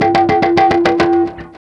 GTR HARM L0P.wav